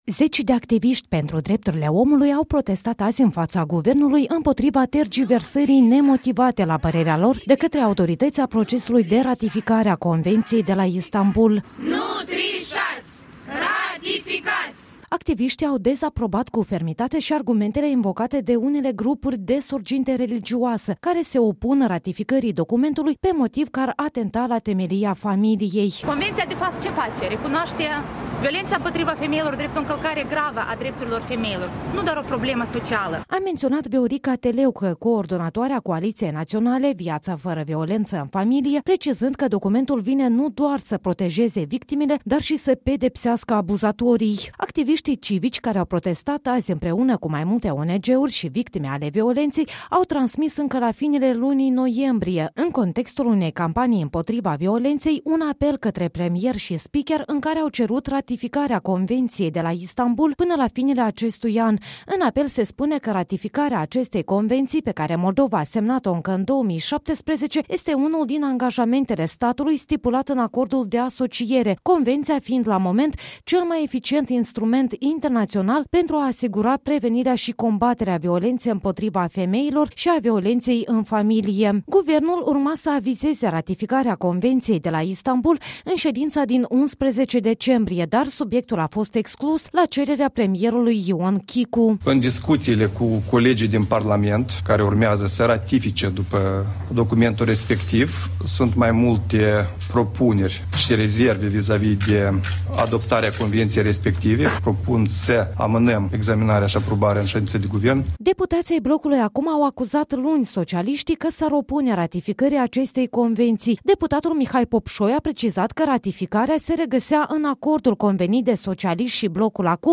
Protest pentru ratificarea Convenției de la Istanbul
S-a scandat: „Ratifica-ţi, nu trişaţi!”, „Ratifica-ţi, nu tergiversa-ţi!”